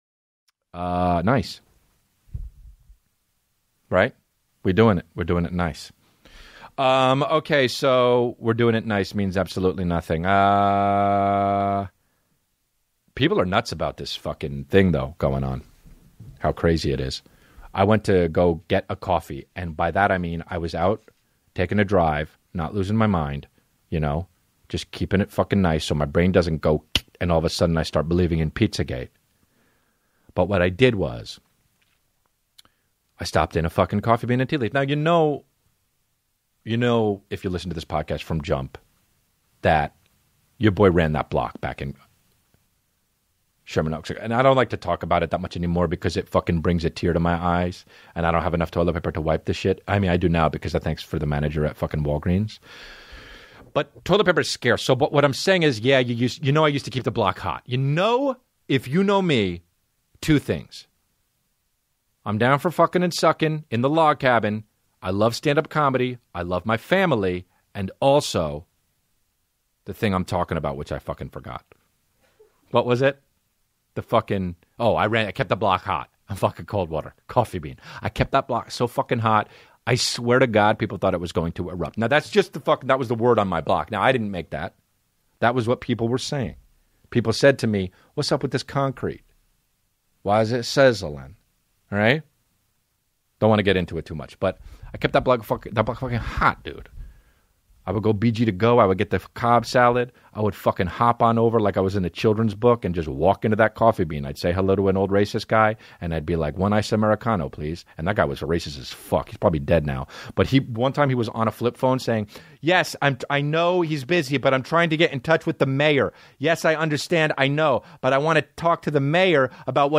Before the first laugh even lands, you should know this: every episode of The Comedy Room places all advertisements right at the beginning, so once the show truly starts, nothing interrupts the flow, the rhythm, or the feeling.